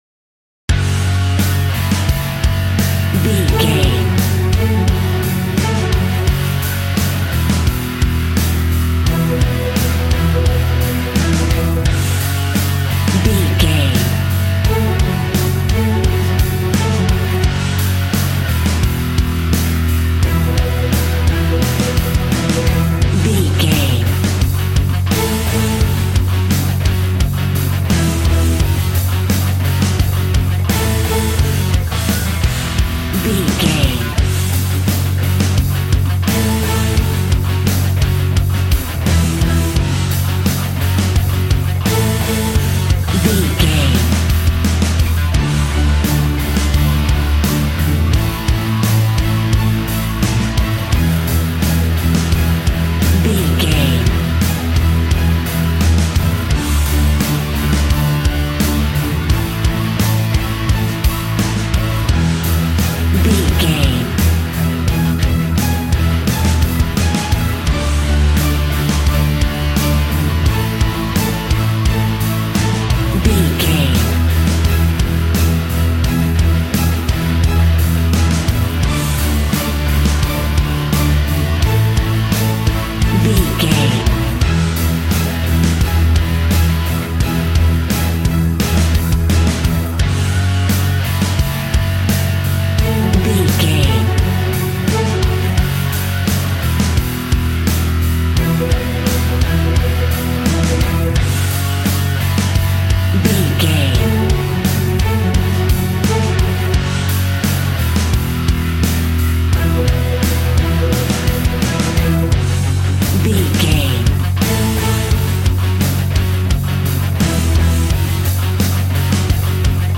Ionian/Major
angry
electric guitar
drums
bass guitar